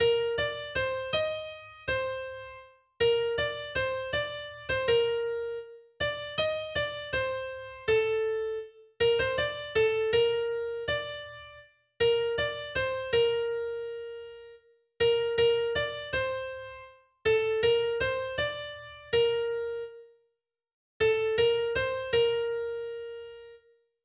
282   "Nur einen Menschen {Diskant}" (Bb-Dur, eigene) .pdf .capx .mid